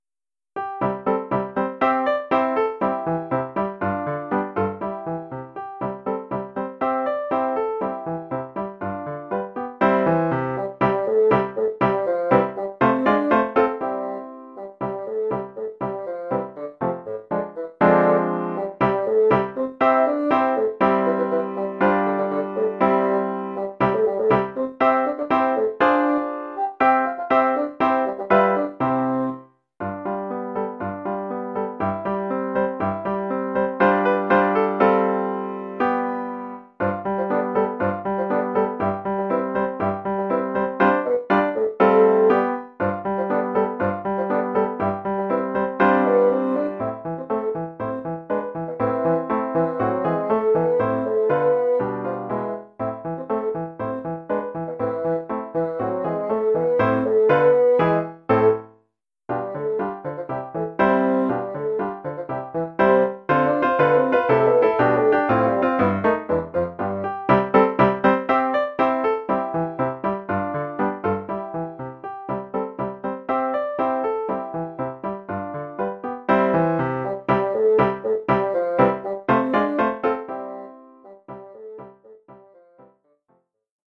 Formule instrumentale : Basson et piano
Oeuvre pour basson et piano.